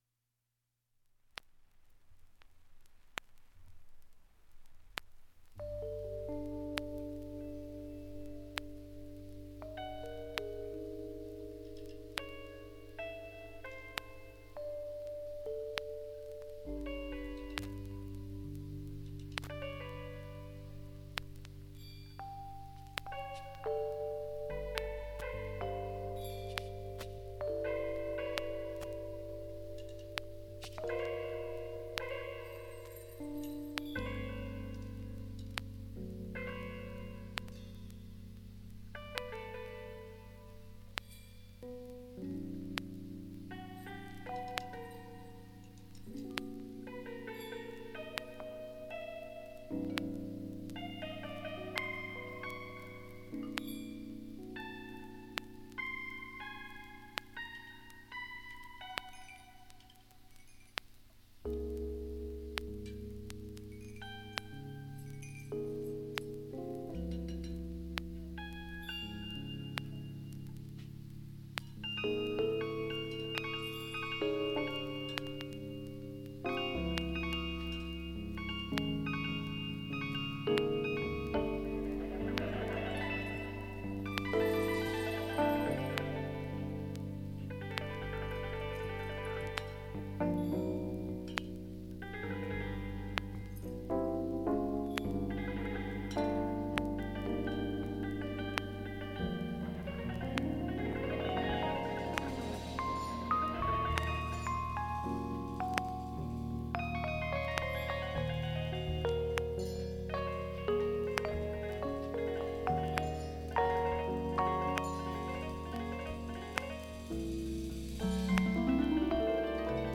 音質良好全曲試聴済み。
２分３０秒の間に周回プツ出ますがかすかです。
A-1中盤に聴き取れないプツが７回出ます。